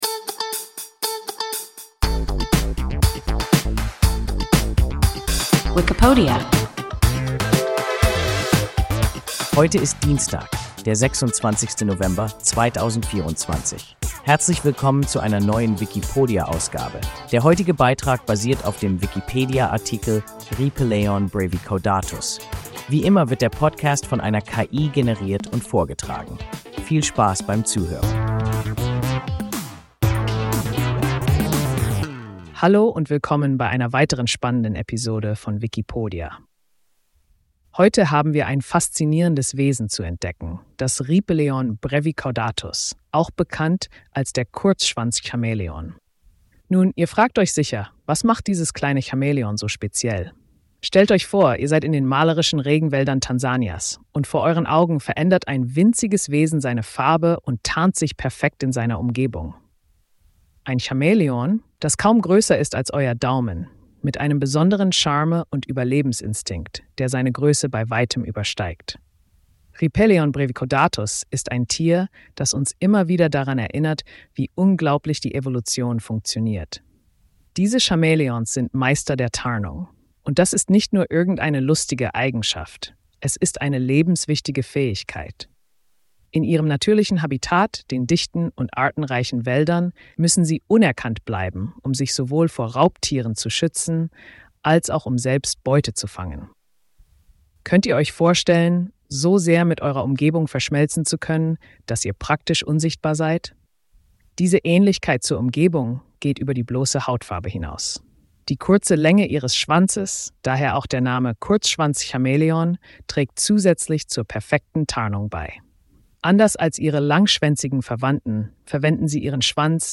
Rieppeleon brevicaudatus – WIKIPODIA – ein KI Podcast